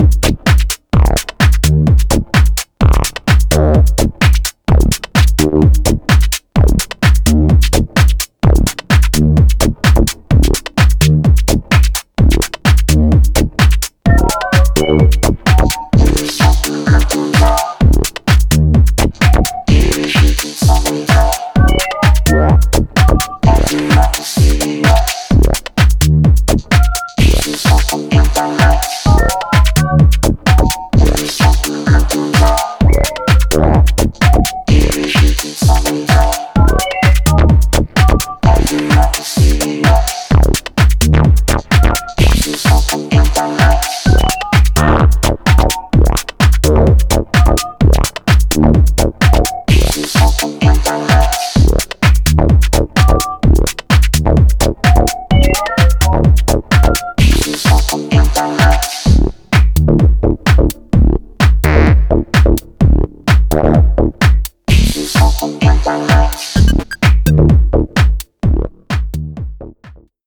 総じてグリッチーでアブストラクト、そしてエキセントリックな音色や、展開の作り込みの緻密さが印象的。